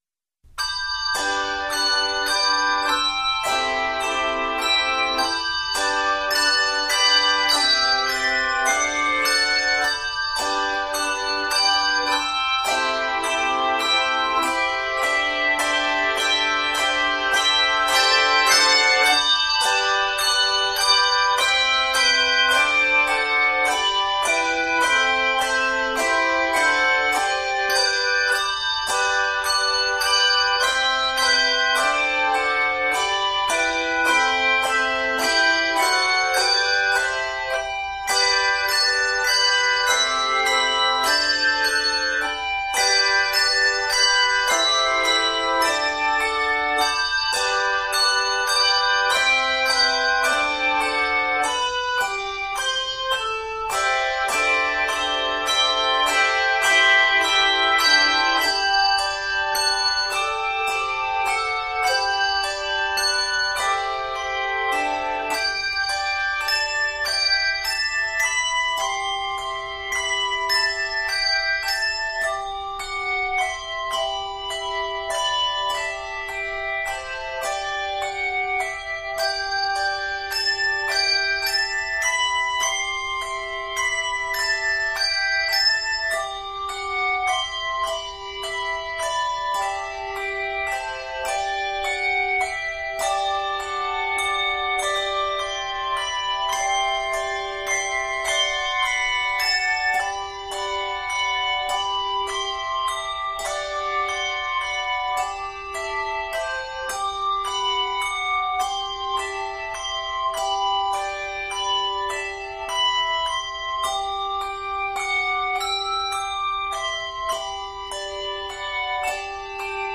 80 measures in length, this setting is scored in G Major.